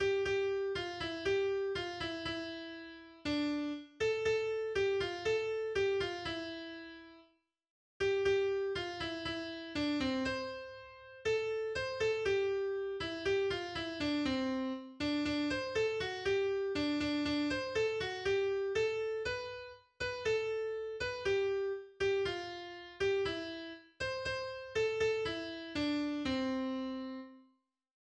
Soldatenlied